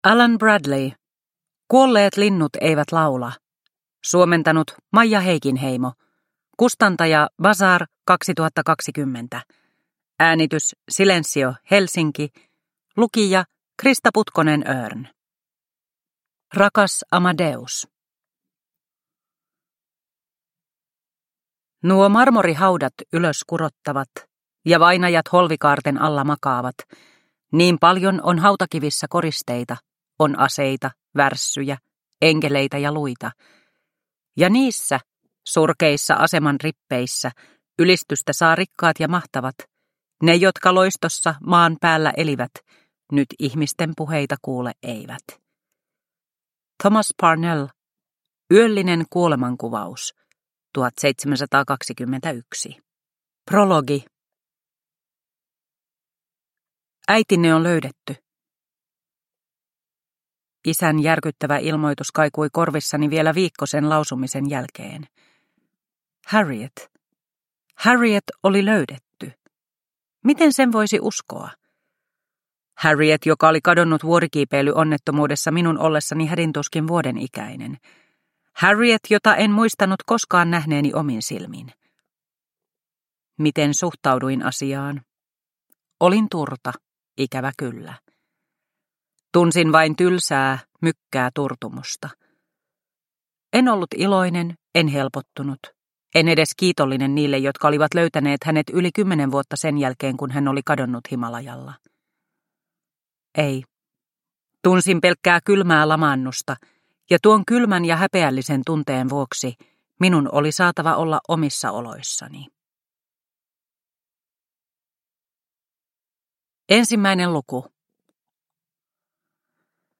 Kuolleet linnut eivät laula – Ljudbok – Laddas ner